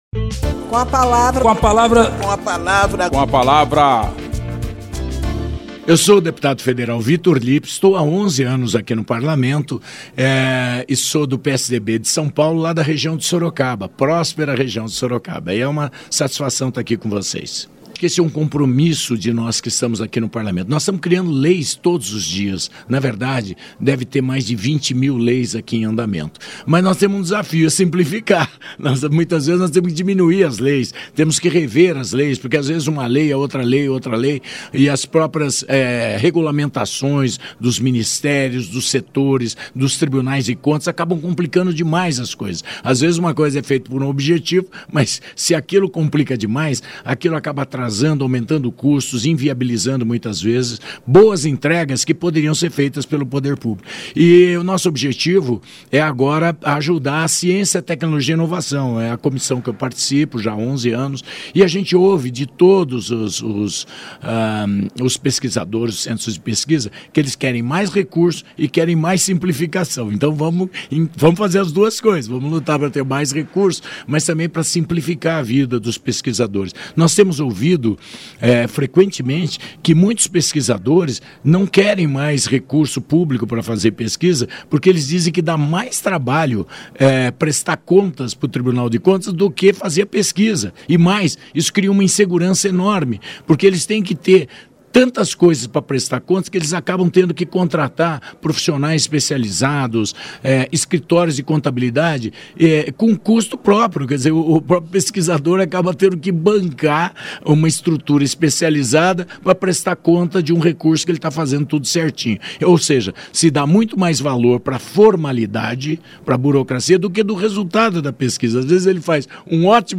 Espaço aberto para que cada parlamentar apresente aos ouvintes suas propostas legislativas